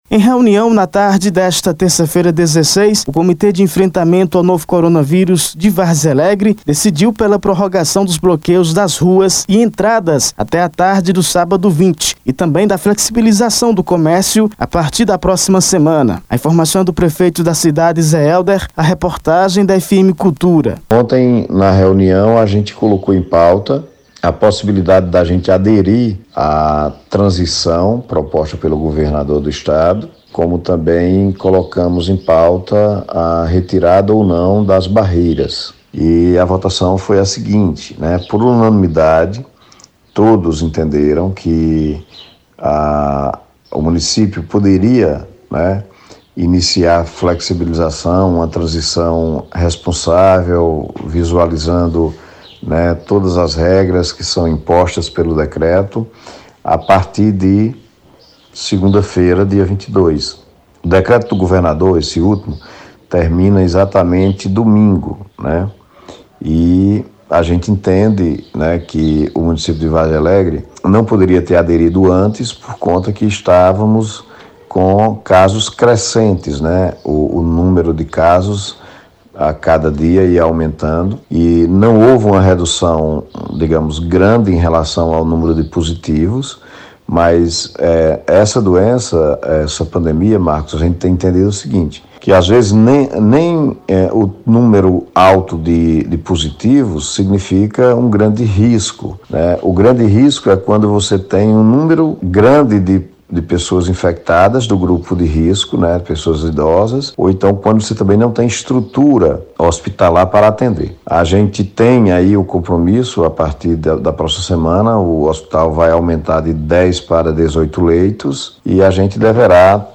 Acompanhe a reportagem completa: